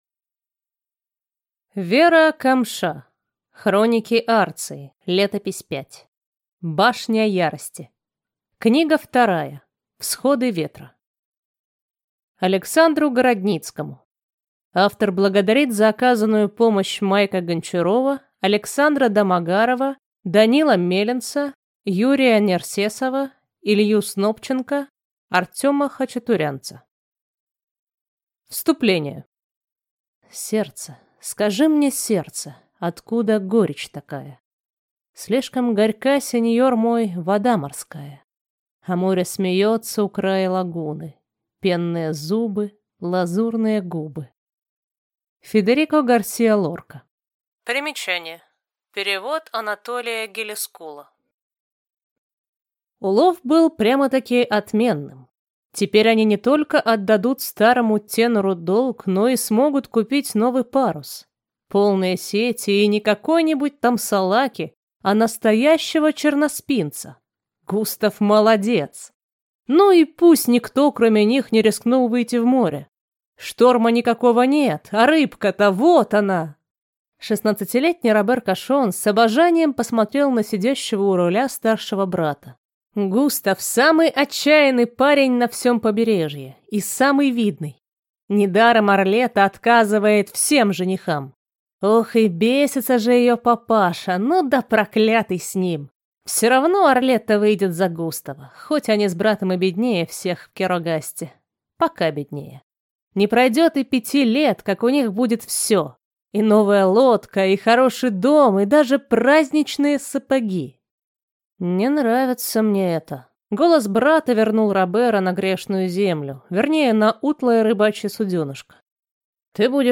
Аудиокнига Башня Ярости. Книга 2. Всходы ветра | Библиотека аудиокниг